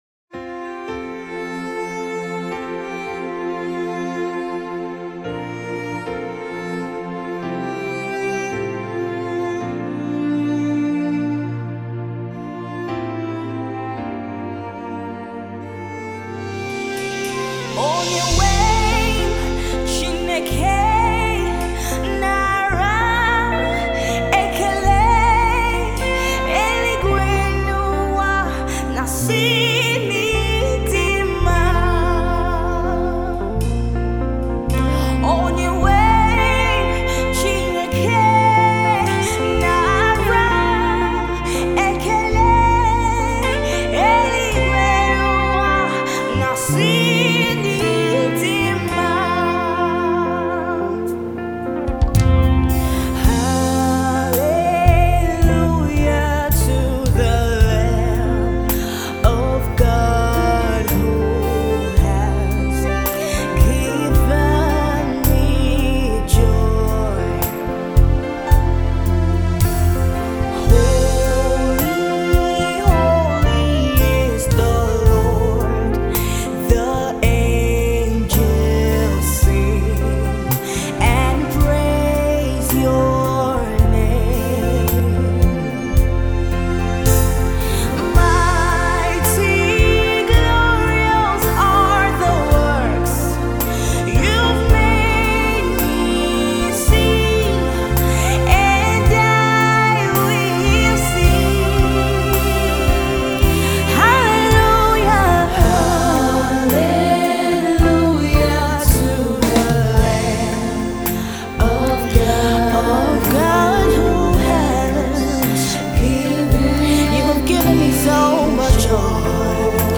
Her sound is unique, powerful and anointed.